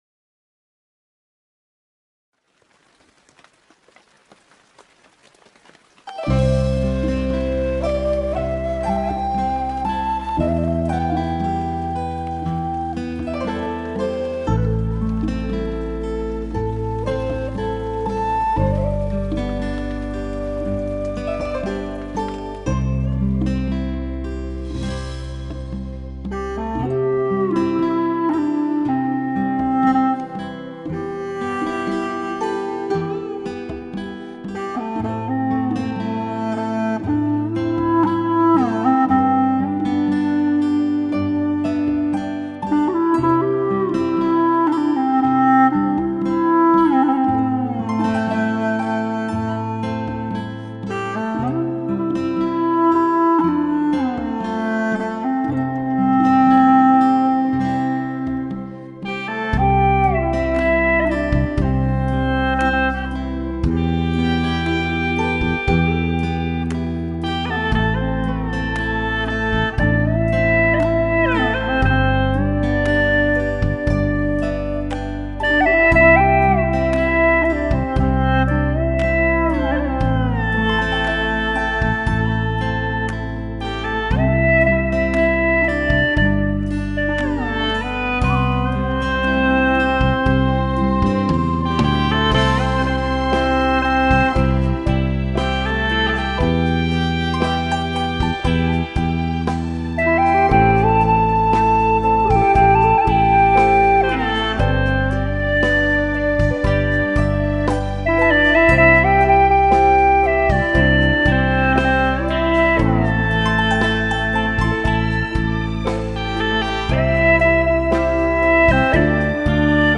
调式 : C 曲类 : 流行
【大小C调】